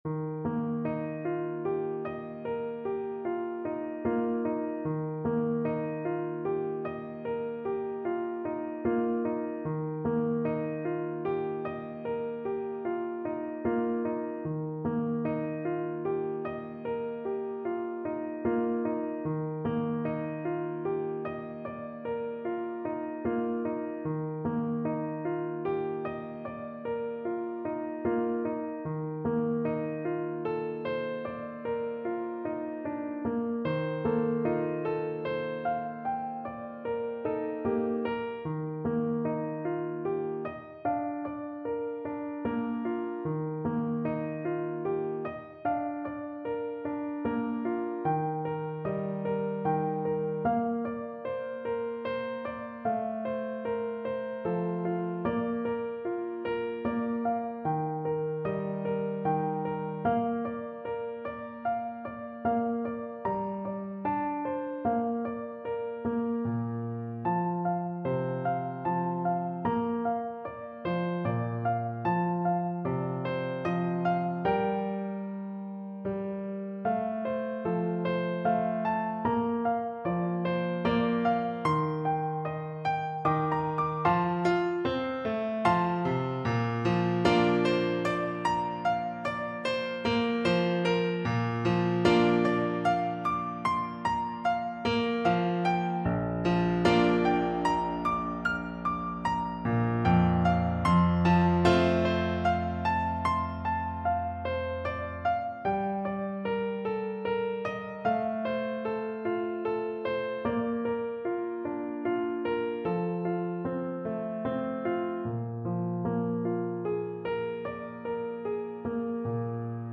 Play (or use space bar on your keyboard) Pause Music Playalong - Piano Accompaniment Playalong Band Accompaniment not yet available transpose reset tempo print settings full screen
Bb major (Sounding Pitch) (View more Bb major Music for Flute )
3/4 (View more 3/4 Music)
Gently Flowing =c.100
Flute  (View more Easy Flute Music)
Traditional (View more Traditional Flute Music)